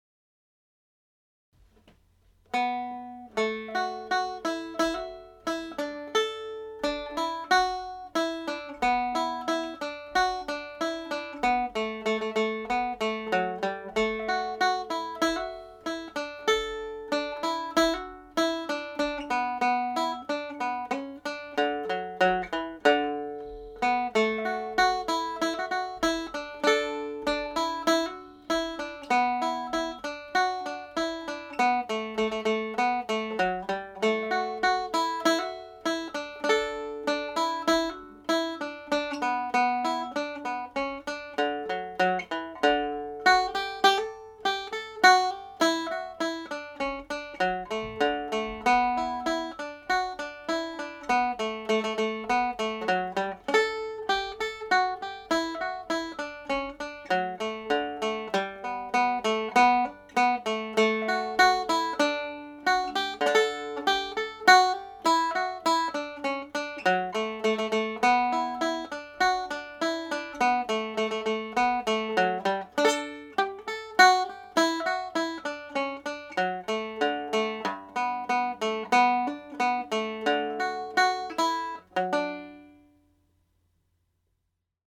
Belfast Trad Advanced class (Mid-Term Break) practice tune
The Air Tune is played as a slow reel in the key of D major.
The Air Tune played as a slow reel